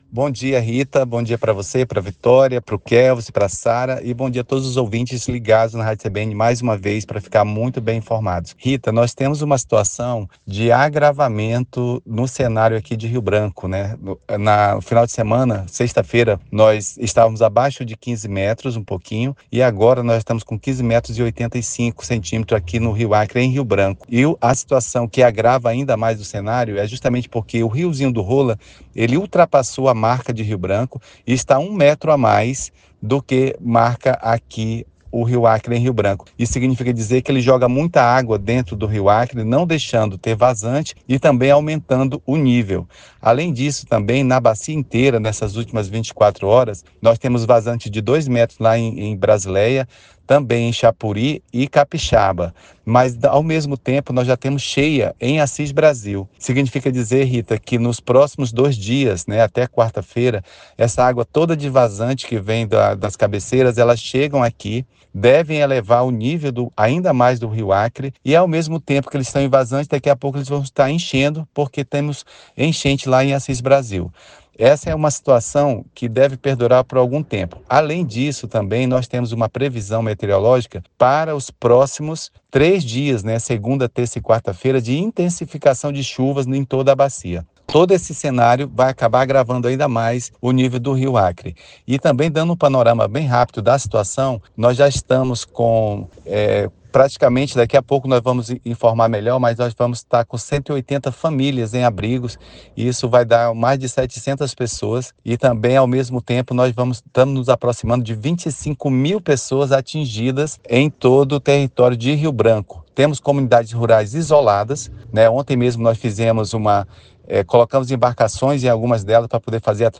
No Jornal da Manhã desta segunda-feira (17), o coordenador da Defesa Civil de Rio Branco, tenente-coronel Cláudio Falcão, trouxe atualizações sobre a elevação do nível do Rio Acre.